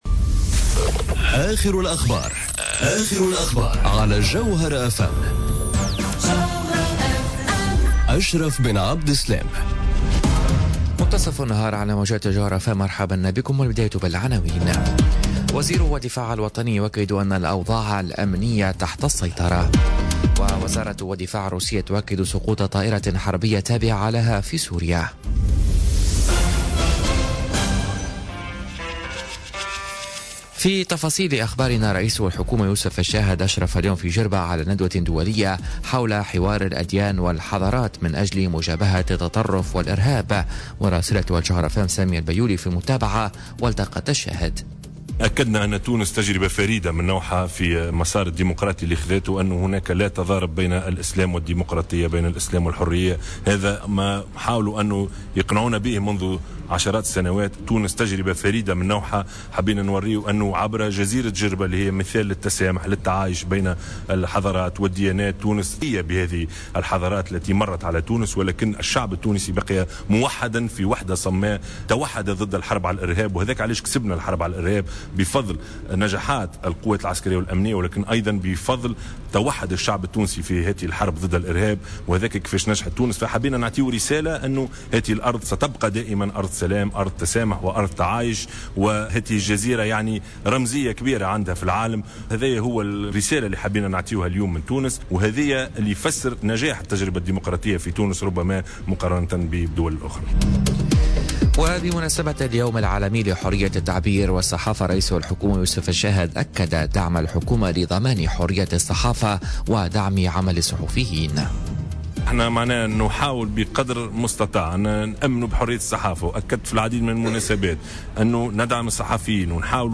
نشرة أخبار منتصف النهار ليوم الخميس 3 ماي 2018